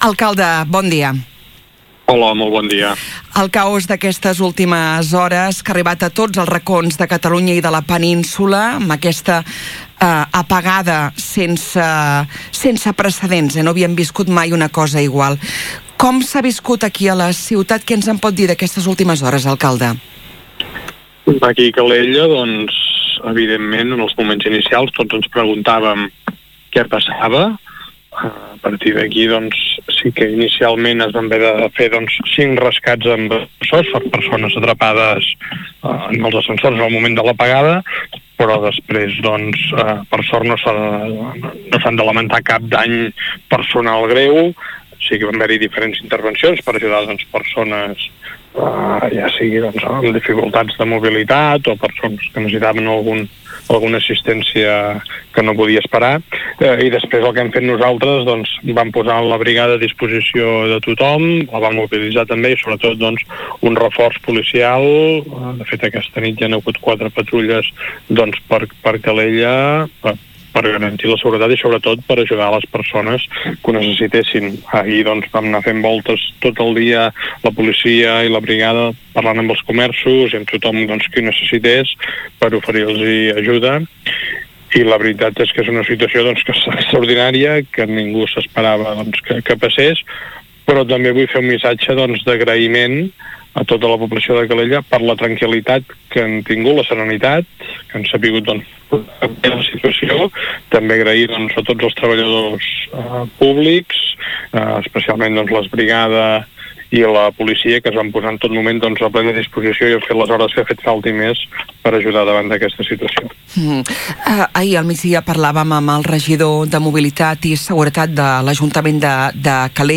L’alcalde Marc Buch ha relatat en una entrevista a l’FM i +, el programa matinal de Ràdio Calella TV, quin va ser l’operatiu especial que es va organitzar ahir per garantir la seguretat de tothom.
A continuació podeu recuperar íntegrament l’entrevista a l’alcalde Marc Buch: